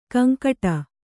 ♪ kaŋkaṭa